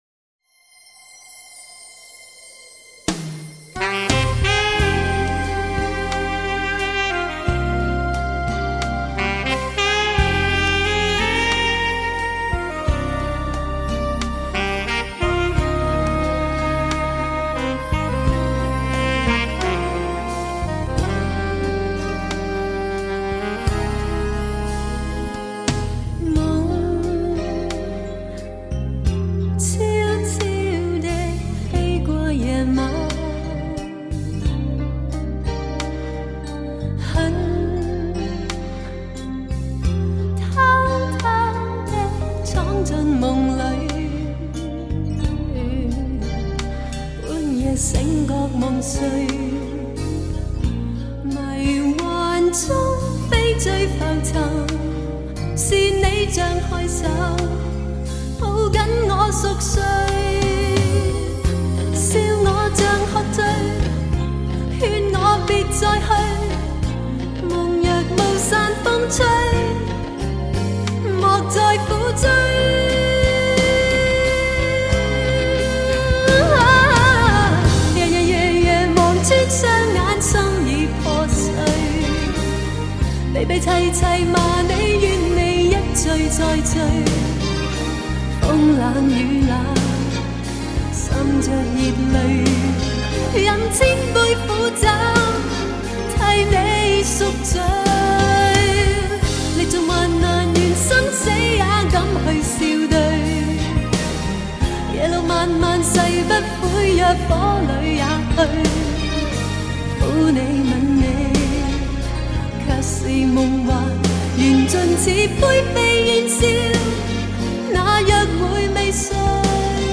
主题曲